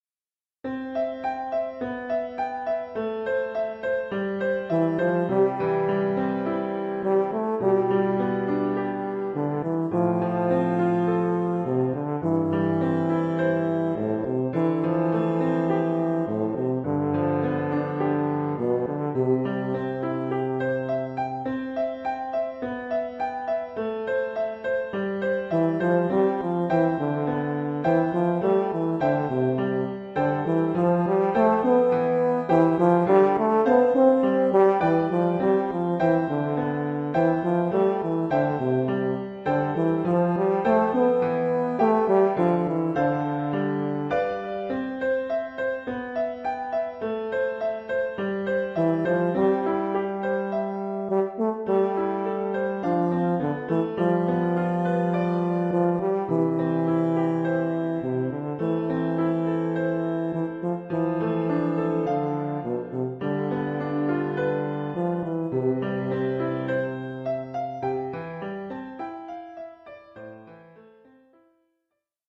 tuba et piano.